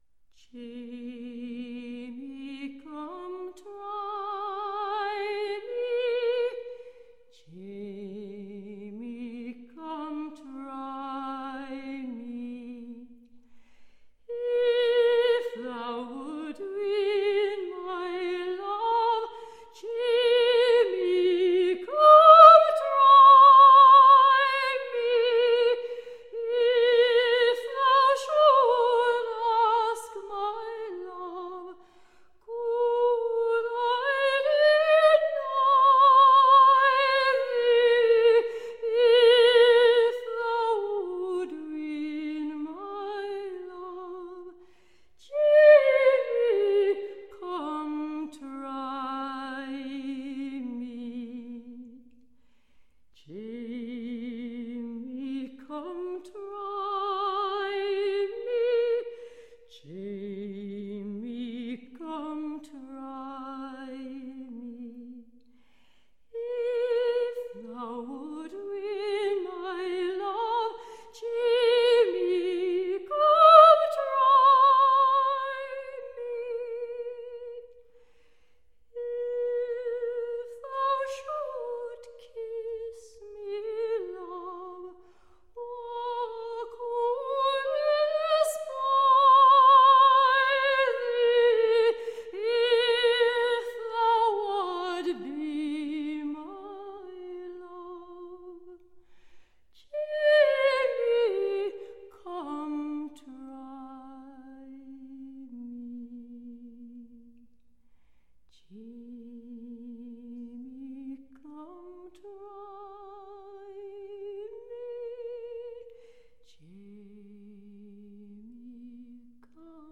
Tagged as: Classical, Folk, Choral, Celtic